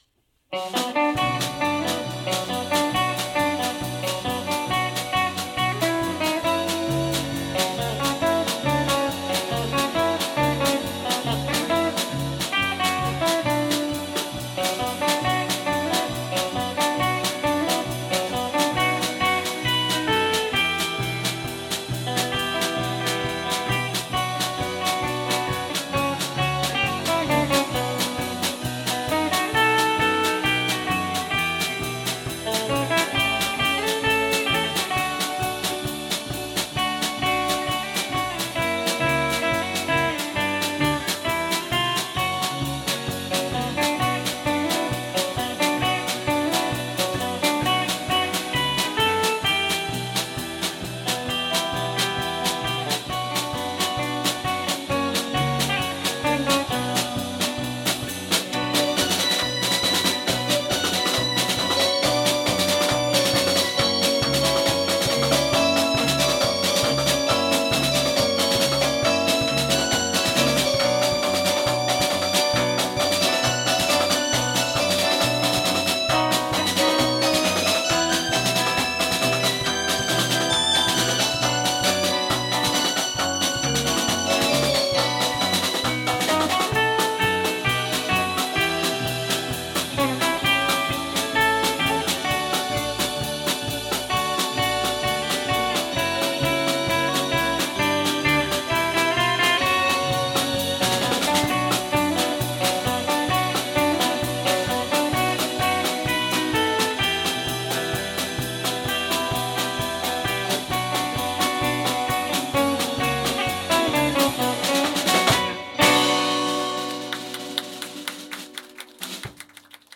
10th anniversary Live
今年は我々のバンドを結成して１０周年になりましたので、10周年記念ライブを開催しました。